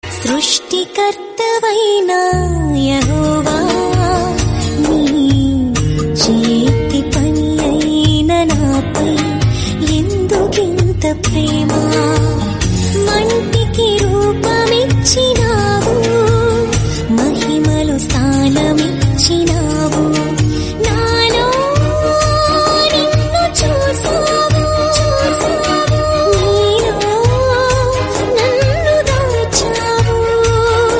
trending ringtonedevotional ringtonemelody ringtone